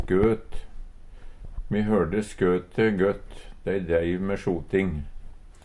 Høyr på uttala Ordklasse: Substantiv inkjekjønn Attende til søk